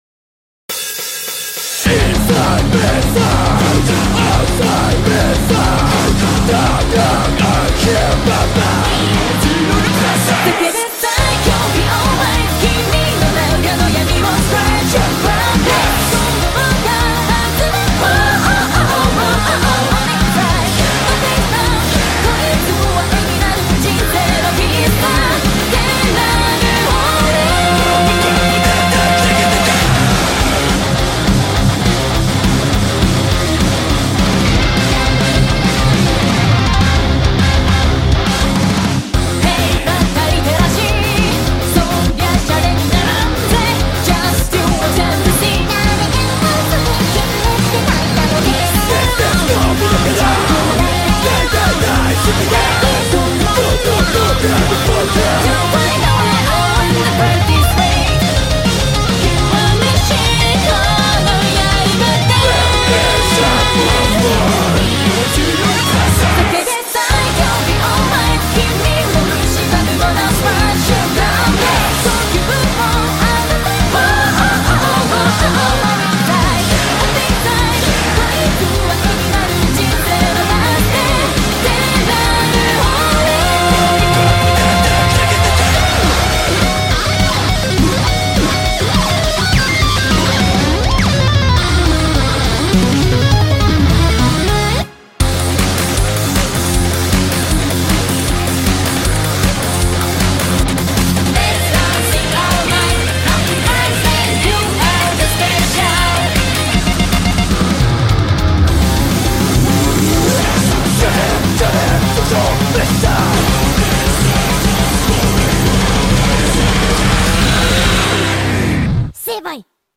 BPM156-206
Audio QualityPerfect (Low Quality)